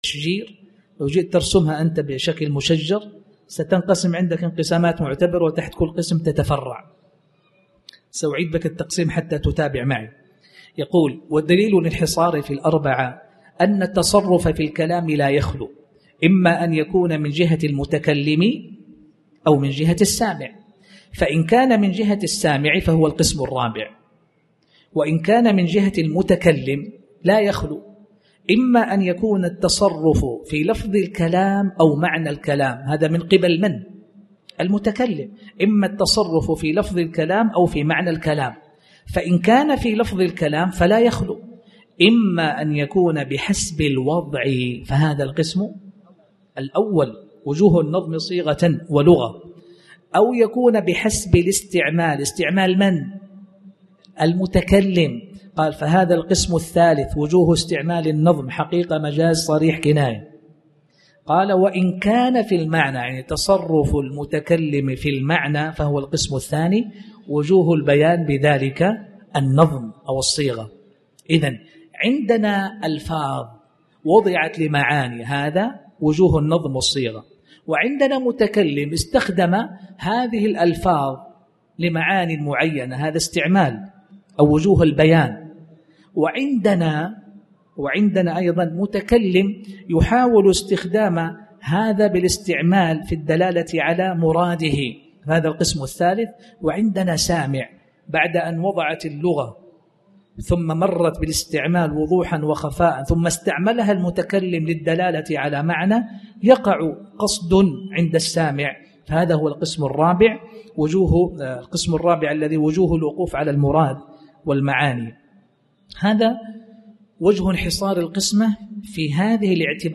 تاريخ النشر ٢٨ محرم ١٤٣٩ هـ المكان: المسجد الحرام الشيخ